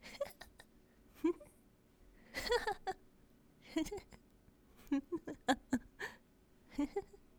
笑2.wav
笑2.wav 0:00.00 0:07.40 笑2.wav WAV · 637 KB · 單聲道 (1ch) 下载文件 本站所有音效均采用 CC0 授权 ，可免费用于商业与个人项目，无需署名。
人声采集素材/人物休闲/笑2.wav